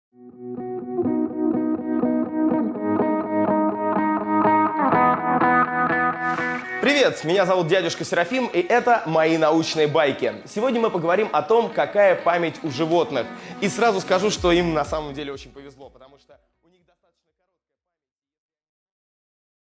Аудиокнига Память у животных | Библиотека аудиокниг
Прослушать и бесплатно скачать фрагмент аудиокниги